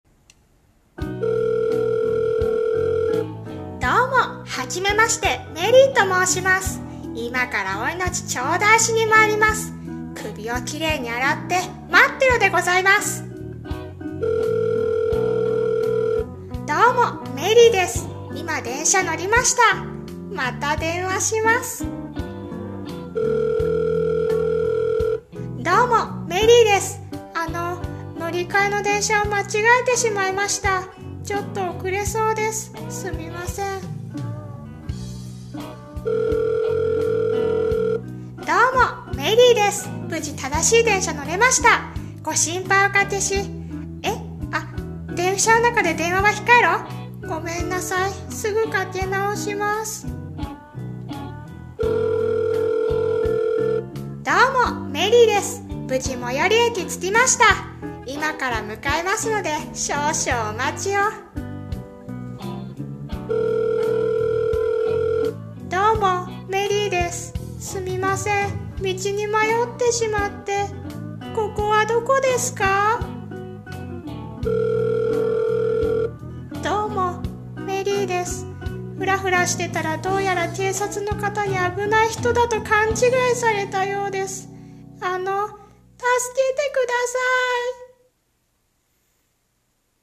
さんの投稿した曲一覧 を表示 【ギャグ声劇台本】どうも、メリーです。